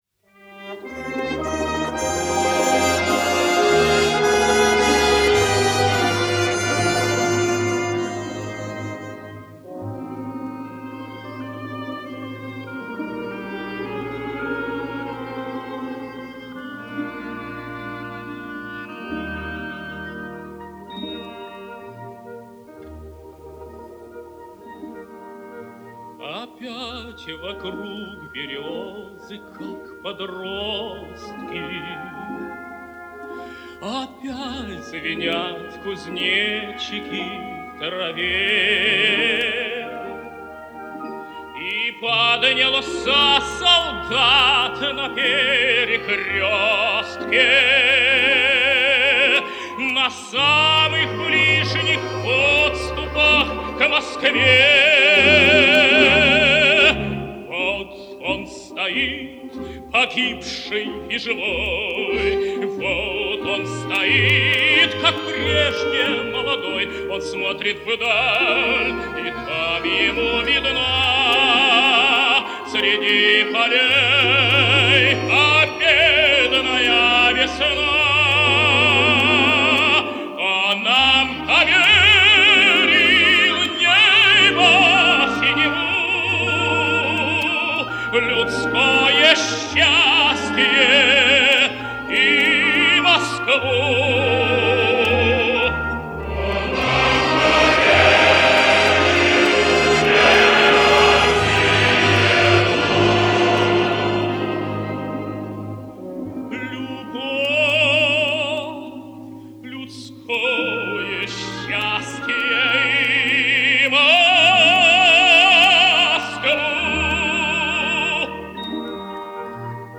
Запись с концерт
солист